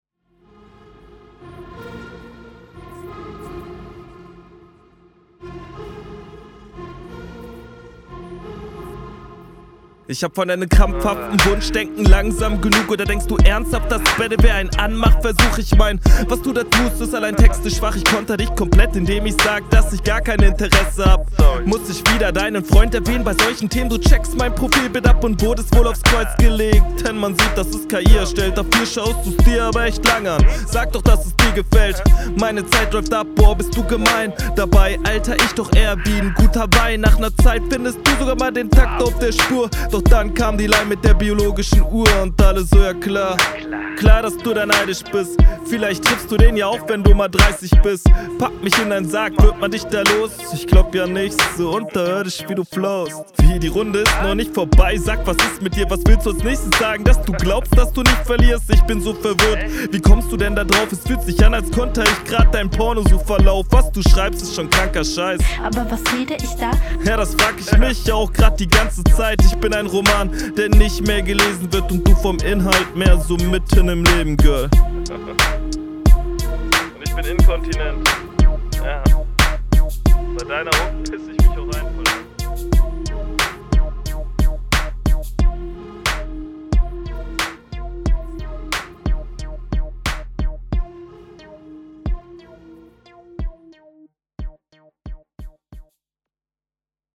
Gut gekontert, gut gerappt alles sehr stark.
Kommst direkt viel besser auf dem Beat.